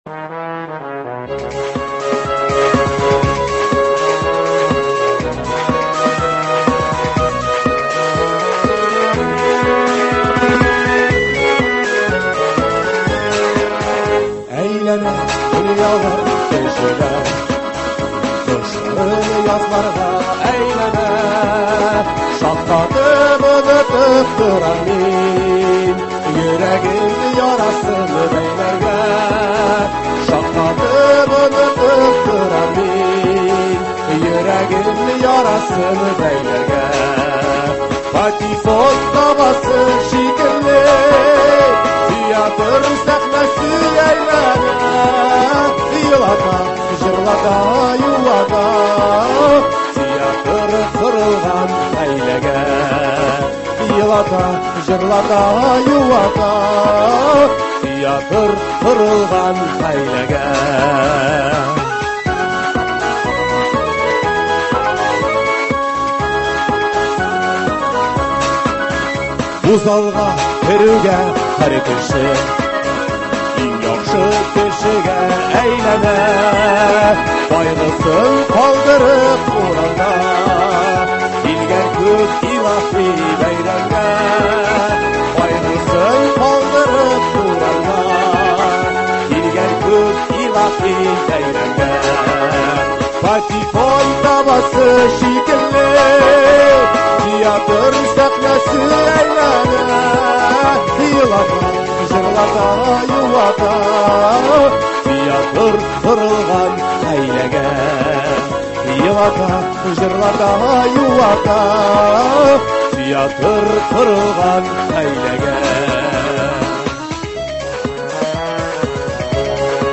Алар белән әңгәмәбез Казанга алып килә торган спектакльләрдән бигрәк театр дөньясының актуаль мәсьәләләренә багышлана.